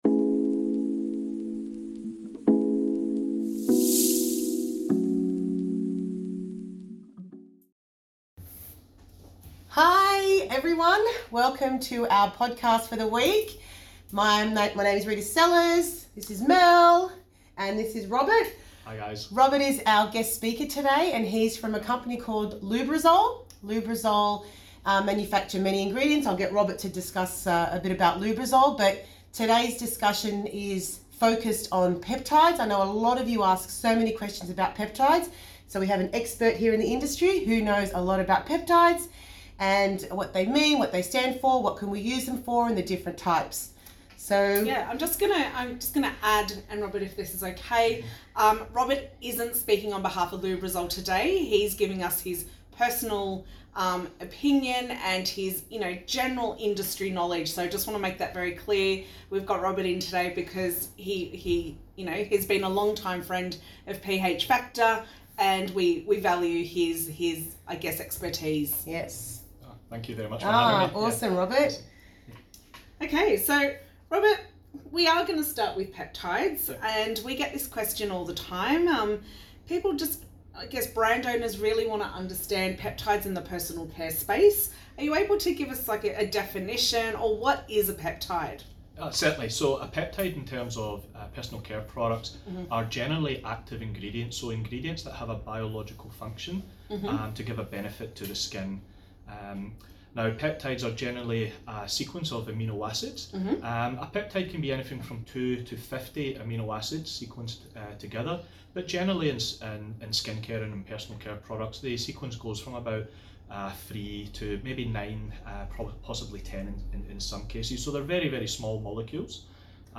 In Conversation with pH Factor Petptides Play Episode Pause Episode Mute/Unmute Episode Rewind 10 Seconds 1x Fast Forward 30 seconds 00:00 / 18:44 Subscribe Share Apple Podcasts RSS Feed Share Link Embed